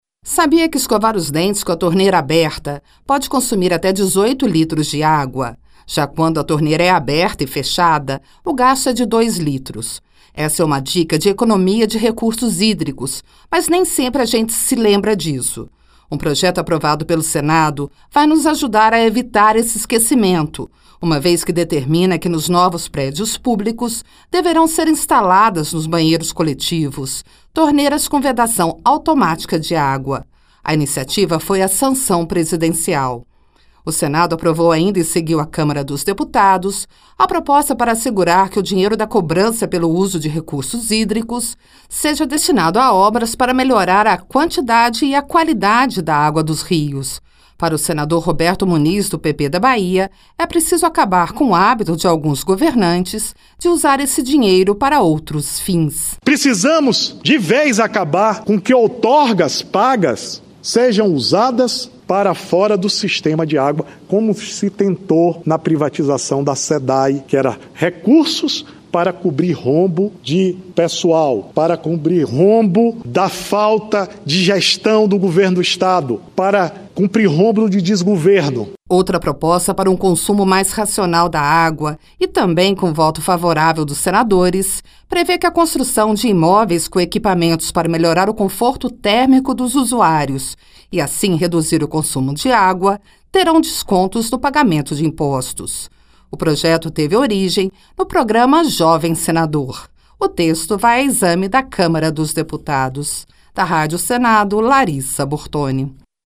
Ouça os detalhes com a repórter